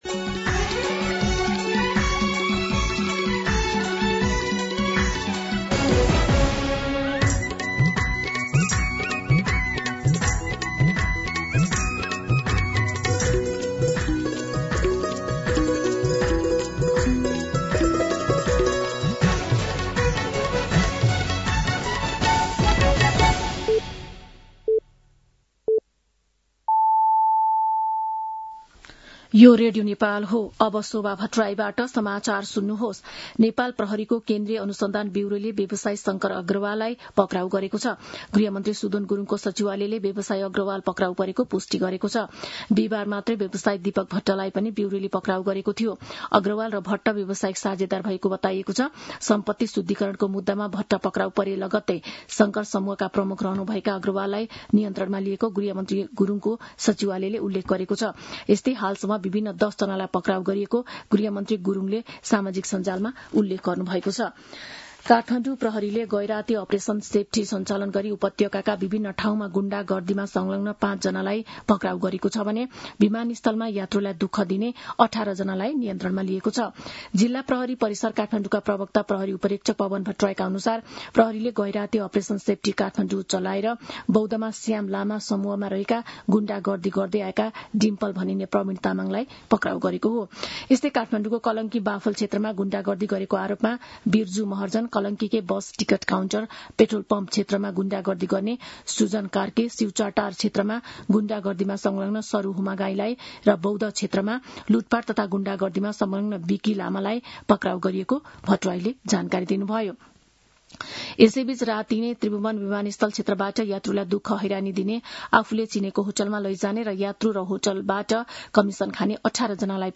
मध्यान्ह १२ बजेको नेपाली समाचार : २१ चैत , २०८२